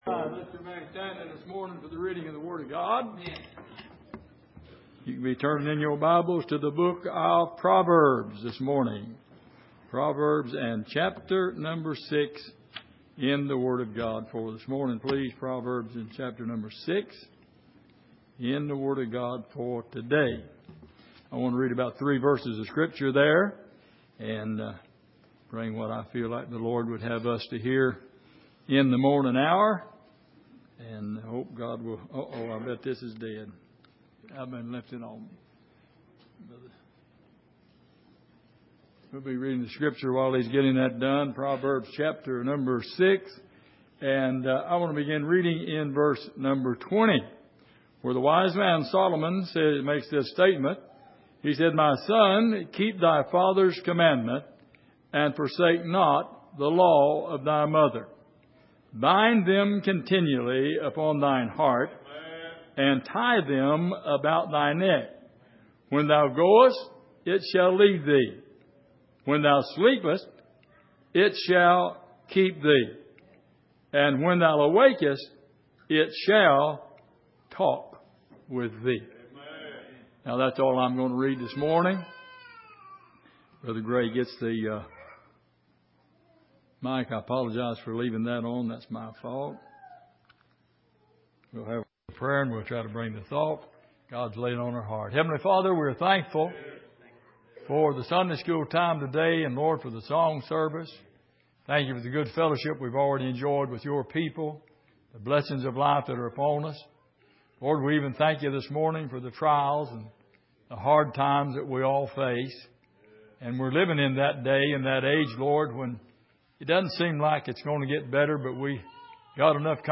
Passage: Proverbs 6:20-22 Service: Sunday Morning